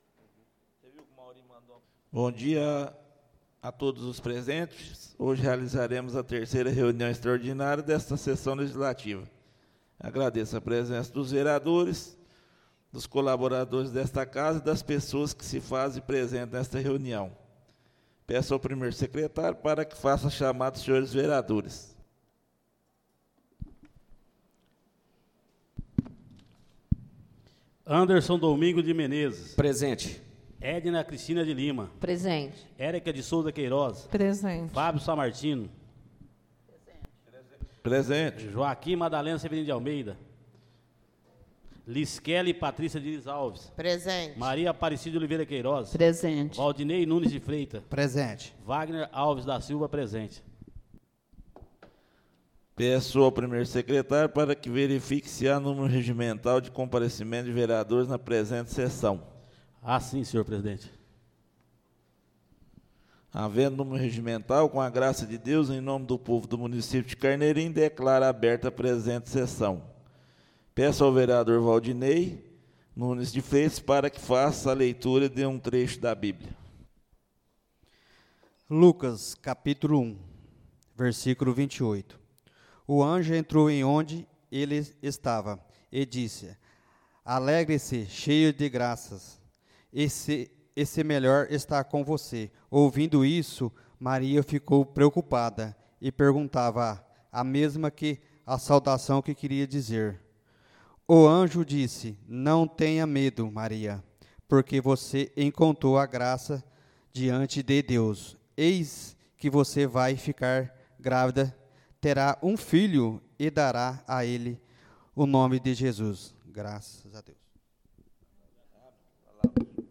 Áudio da 03.ª reunião extraordinária de 2025, realizada no dia 19 de Março de 2025, na sala de sessões da Câmara Municipal de Carneirinho, Estado de Minas Gerais.